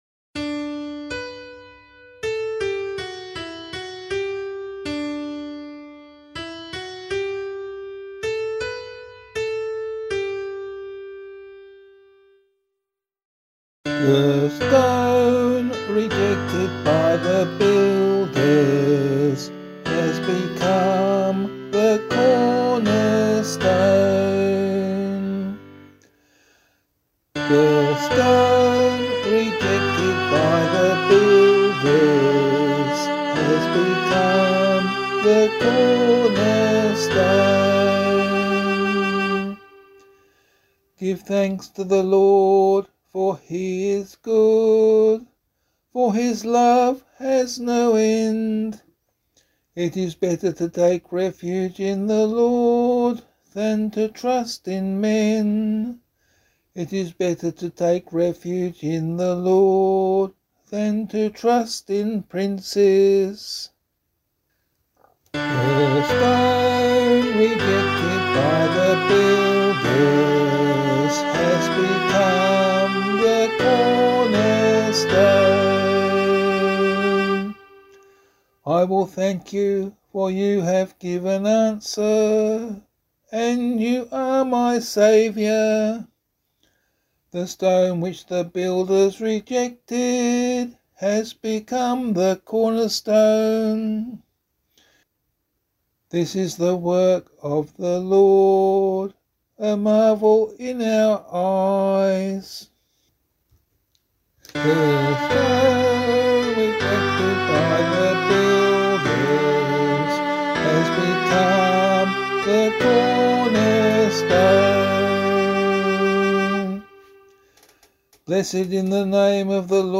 026 Easter 4 Psalm B [LiturgyShare 7 - Oz] - vocal.mp3